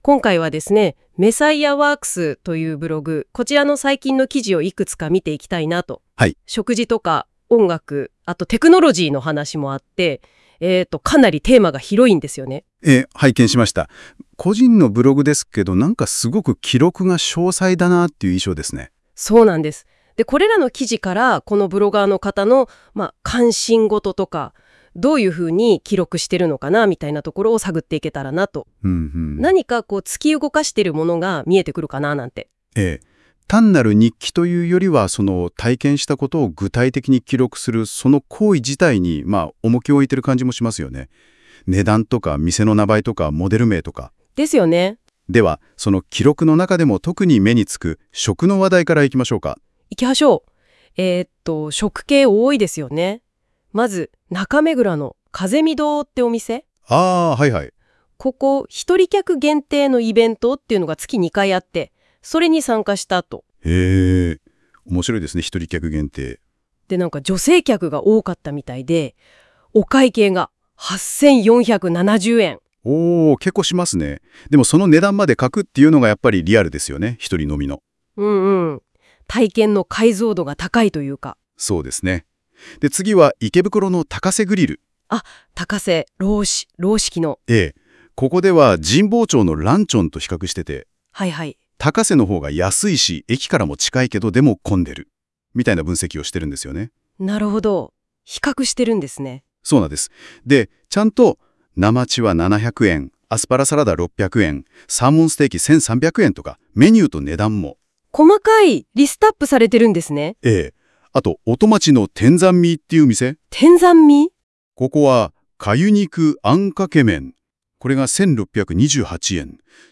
GoogleのAIノート「NotebookLM」で、このブログの男女掛け合いラジオ(podcast音声)を自動生成してみた。
かなり、それっぽい感じ！